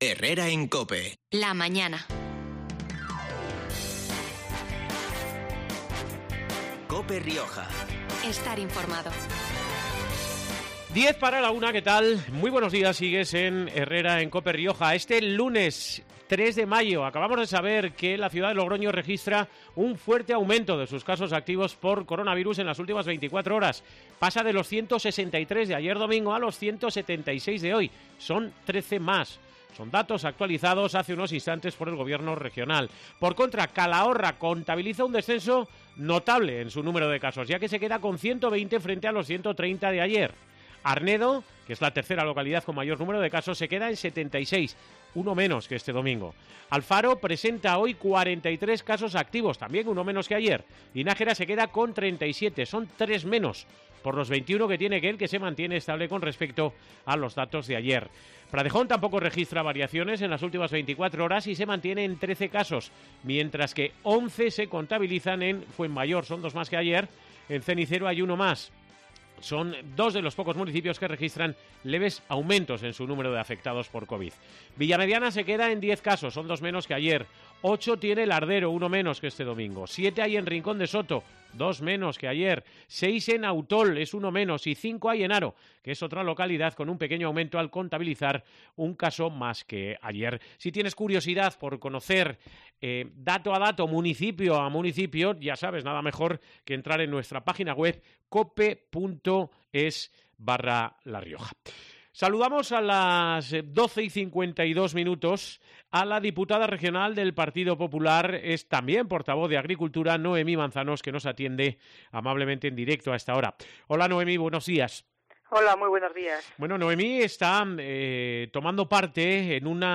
Un encuentro del que ha informado este mediodía en COPE Rioja la diputada regional, Noemí Manzanos , quien ha subrayado que “ la integridad de la Denominación de Origen Calificada (DOCa) Rioja nunca puede ser moneda de cambio entre Sánchez y el PNV para sus pactos políticos".